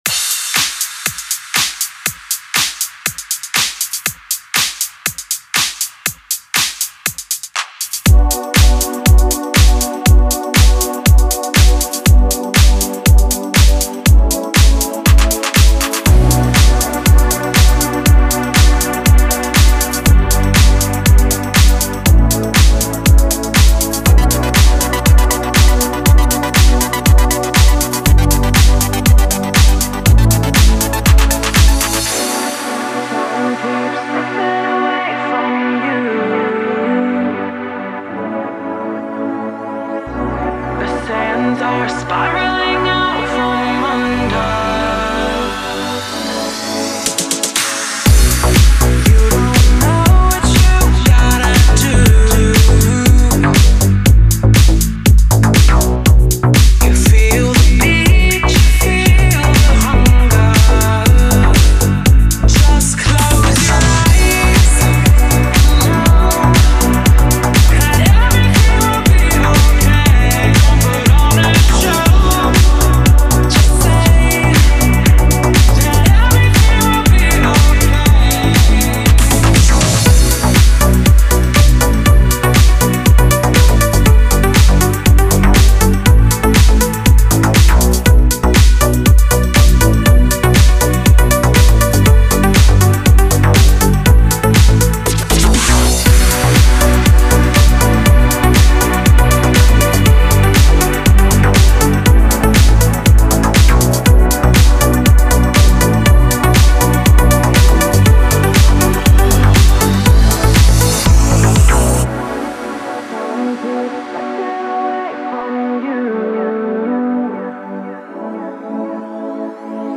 это энергичная электронная композиция в жанре house